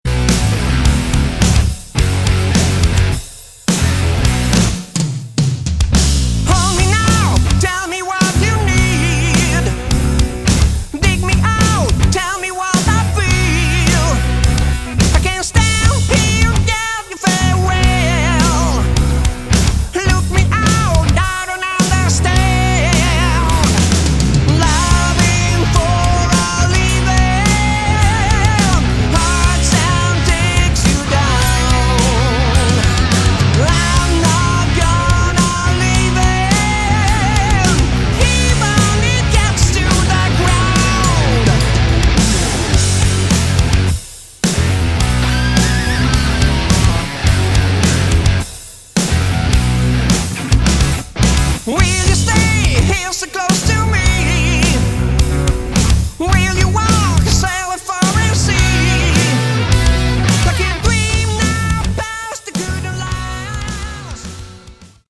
Category: Melodic Metal
vocals
drums
guitar, bass